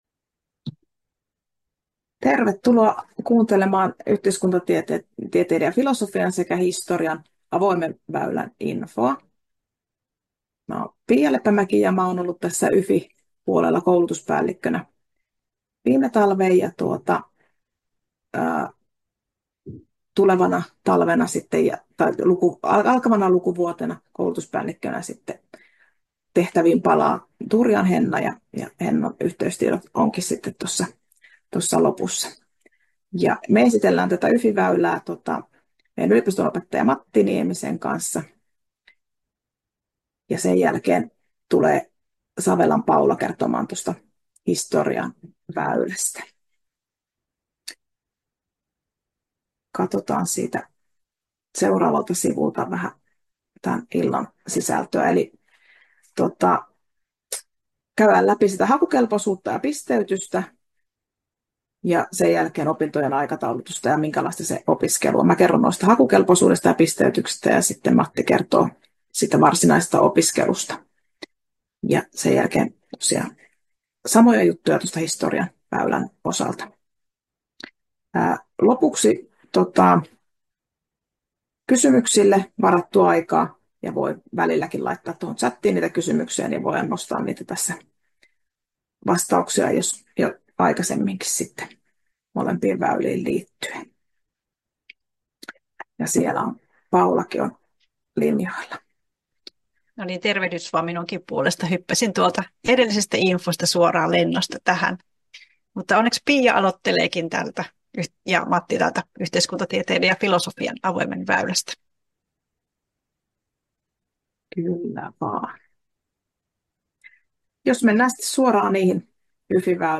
Pahoittelemme heikkoa äänenlaatua historian väylän osuudessa.